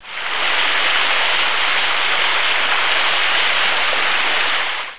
Folder: water
forest1.wav